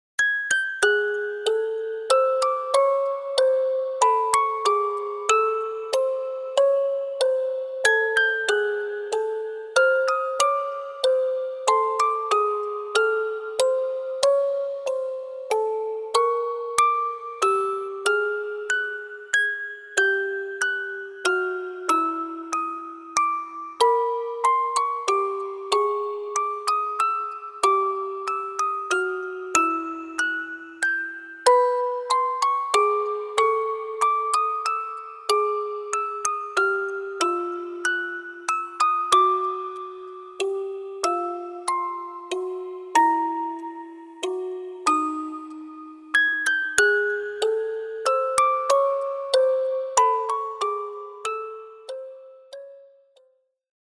フリーBGM イベントシーン ホラー・不気味・不穏
フェードアウト版のmp3を、こちらのページにて無料で配布しています。